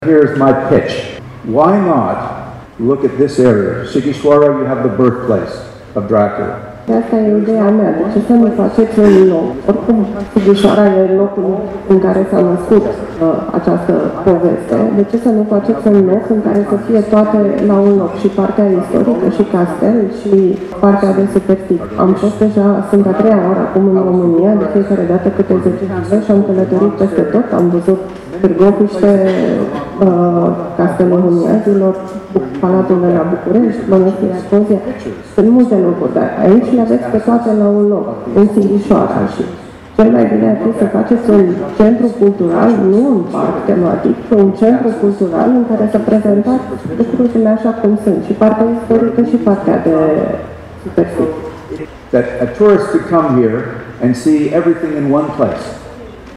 Conferința internațională „JUDEȚUL MUREȘ – BRAND LOADING” a fost dedicată unor invitați din țară și din străinătate, care au vorbit despre felul în care văd județul Mureș și despre oportunitățile pe care acesta le are, pentru crearea unui brand de regiune.